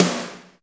• Reverb Rock Snare Drum Sample B Key 162.wav
Royality free snare sound tuned to the B note. Loudest frequency: 2919Hz
reverb-rock-snare-drum-sample-b-key-162-RmG.wav